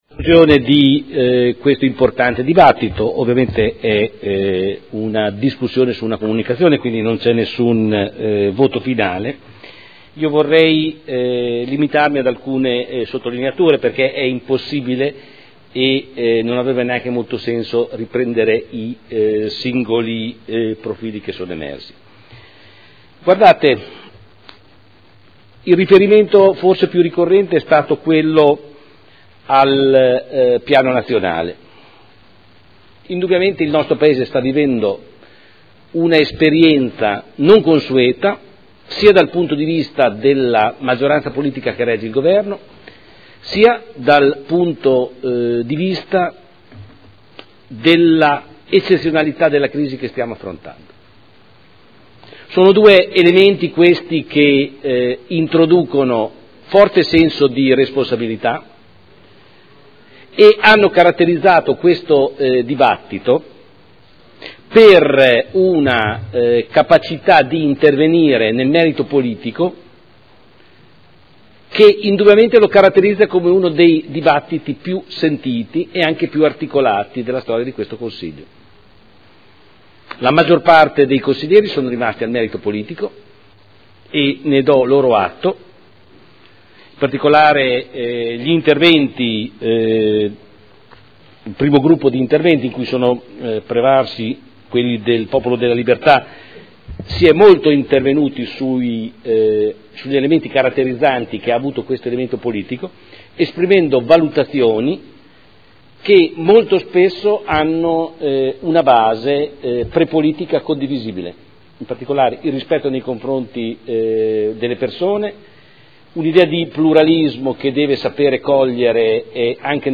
Seduta del 23/04/2012. Conclude comunicazione del Sindaco sulla composizione della Giunta.